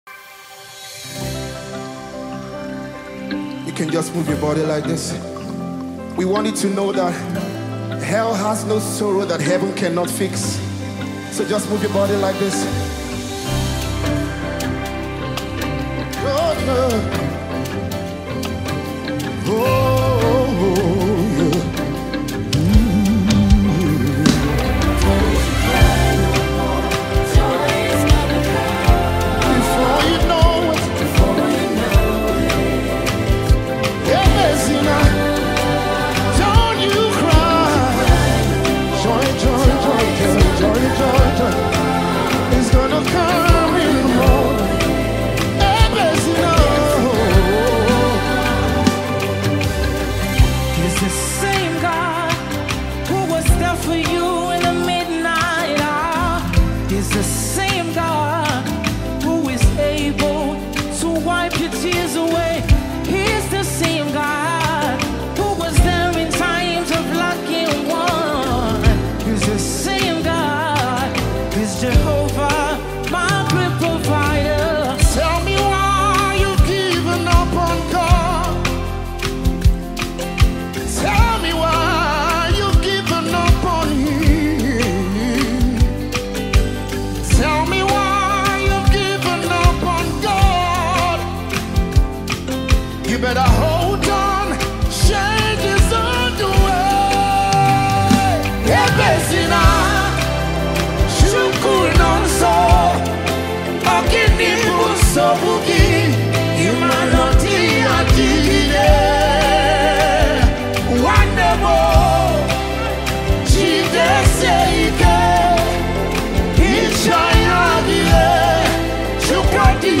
moving melody